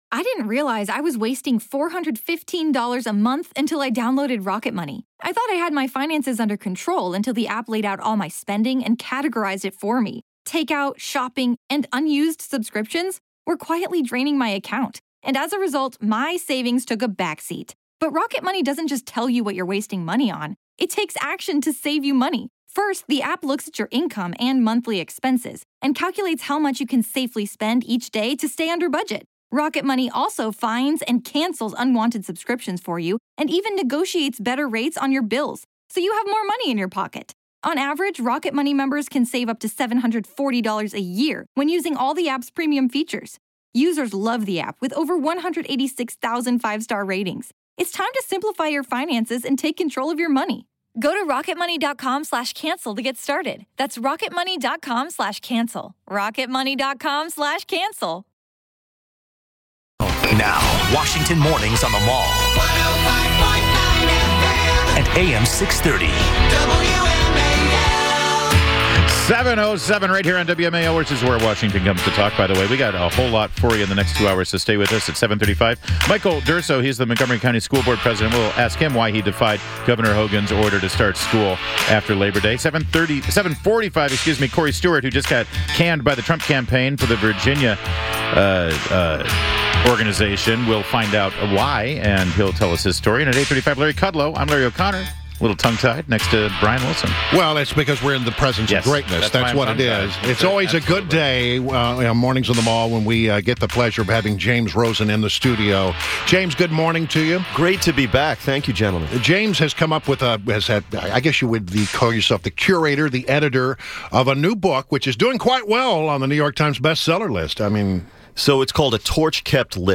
INTERVIEW — JAMES ROSEN — Fox News Chief Washington Correspondent; editor of A Torch Kept Lit by William F. Buckley, Jr.